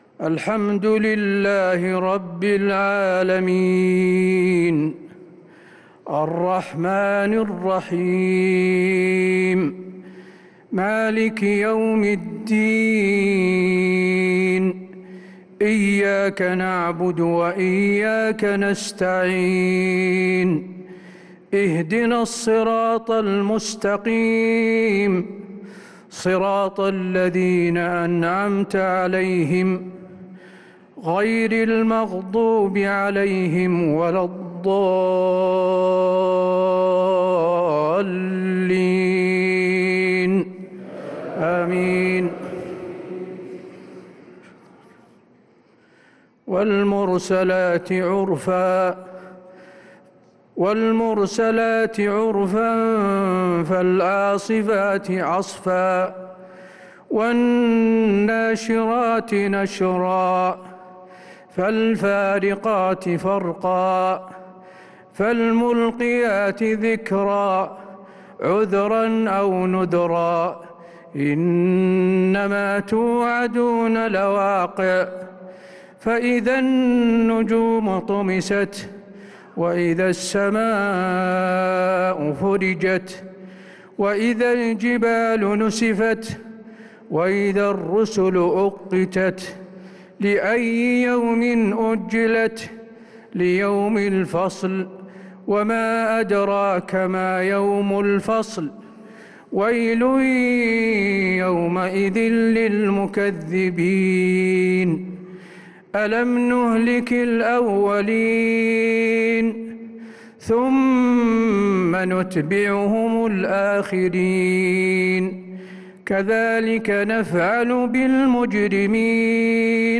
عشاء السبت 1-9-1446هـ سورة المرسلات كاملة | Isha prayer Surat al-Mursalat 1-3-2025 > 1446 🕌 > الفروض - تلاوات الحرمين